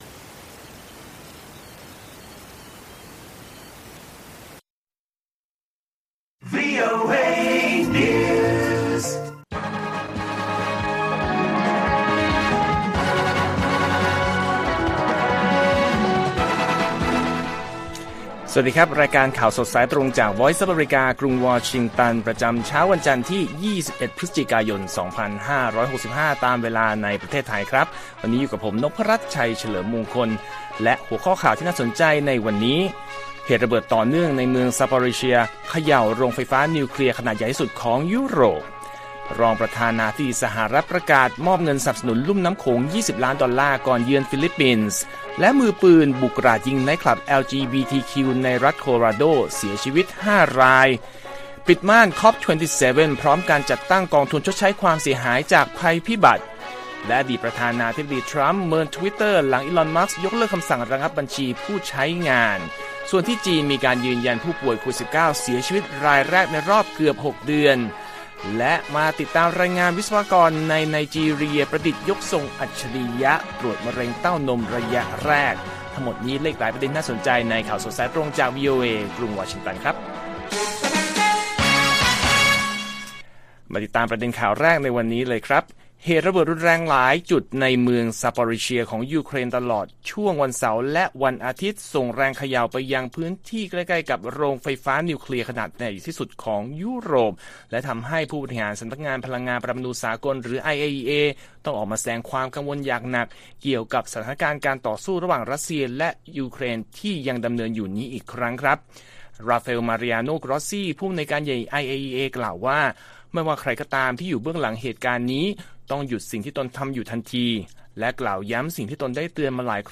ข่าวสดสายตรงจากวีโอเอไทย วันจันทร์ ที่ 21 พ.ย. 2565